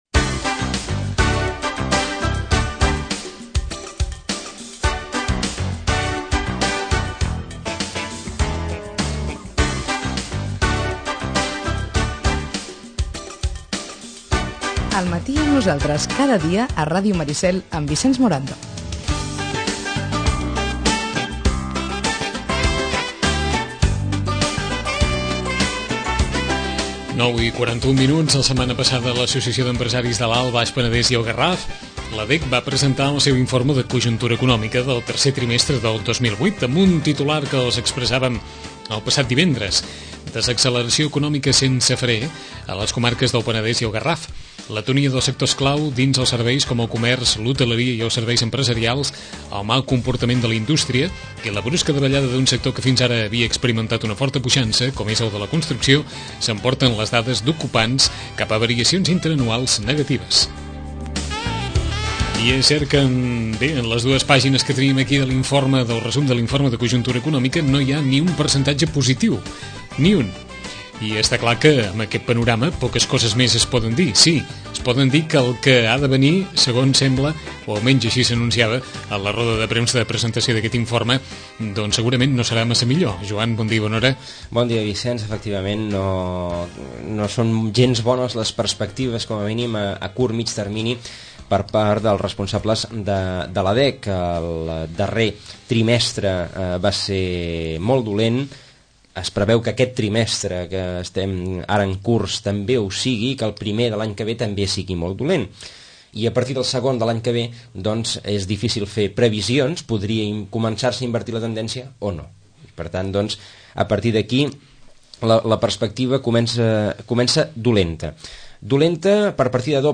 Acte de presentació de l’informe de cojuntura econòmica del 3r trimestre del 2008, encarregat per l’Associació d’Empresaris de l’Alt, Baix Penedès i el Garraf. El document posa en evidència la contundent desacceleració econòmica que viuen totes les comarques analitzades.